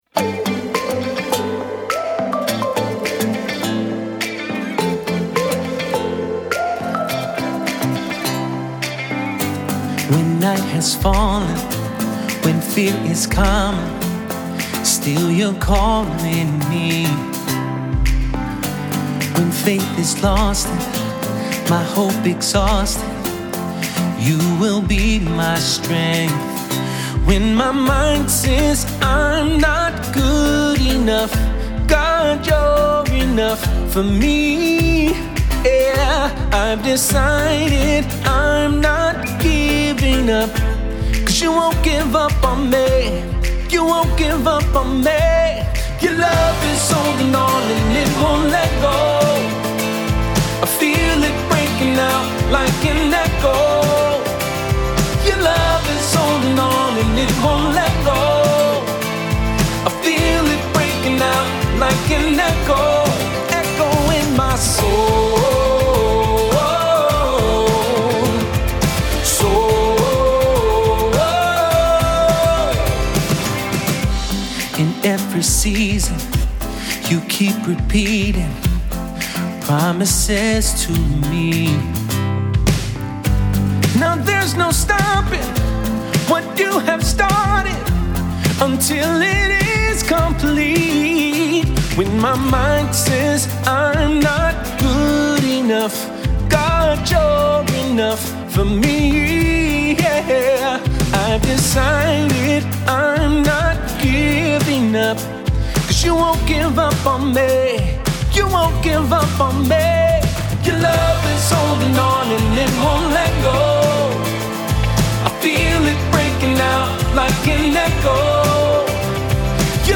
Full arrangement demo
5. Reference mp3’s with lead vocals
• Tempo: 104 bpm, 4/4 time
STYLE: FULL PRODUCTION
• Drums
• Percussion
• Acoustic Guitar
• Bass
• Electric Guitars (2 tracks)
• Gang Vocals
• Lead Synths
• Pads
• Piano